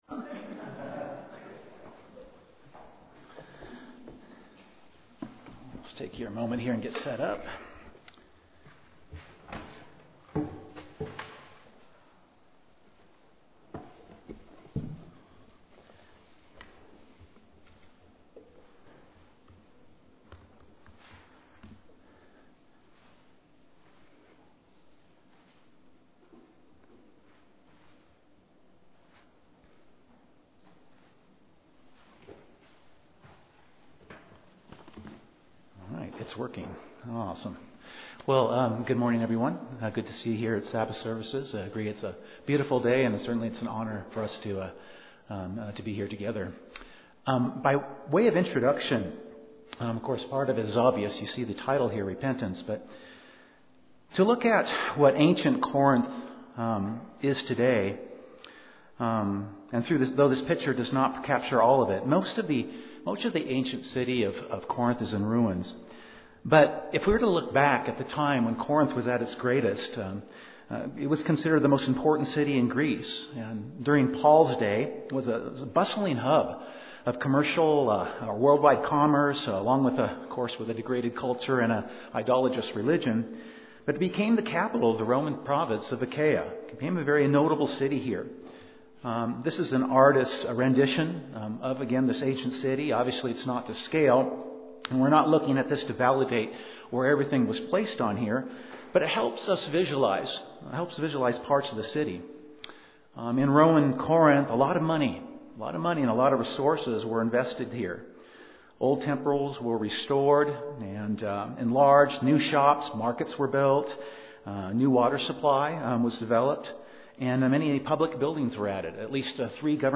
And in 2 Corinthians 7:11 he describes 7 facets of repentance that the majority of those in the church at Corinth went through. This sermon will review those 7 facets of repentance and what we can learn from it for our own repentance.